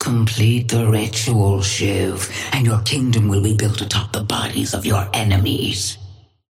Sapphire Flame voice line - Complete the ritual, Shiv, and your kingdom will be built atop the bodies of your enemies.
Patron_female_ally_shiv_start_02.mp3